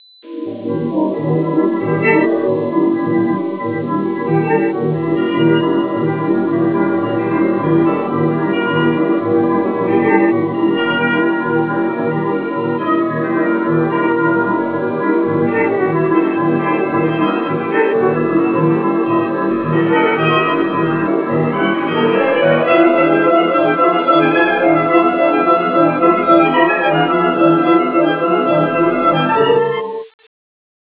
una ritmata pagina musicale
Original track music